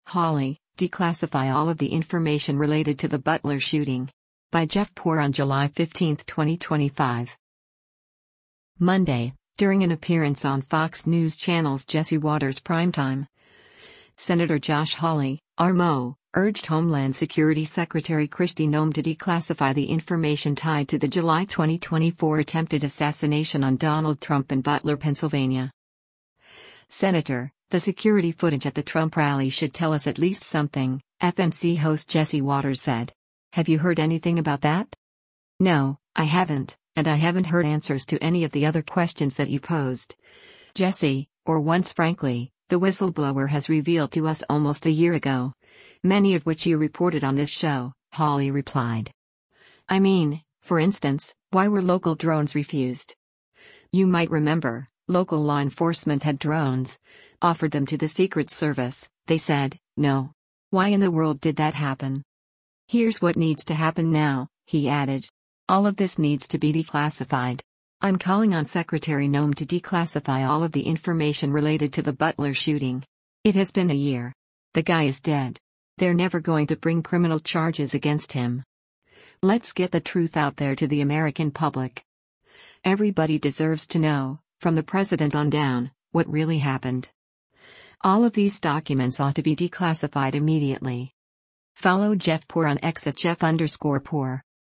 Monday, during an appearance on Fox News Channel’s “Jesse Watters Primetime,” Sen. Josh Hawley (R-MO) urged Homeland Security Secretary Kristi Noem to declassify the information tied to the July 2024 attempted assassination on Donald Trump in Butler, PA.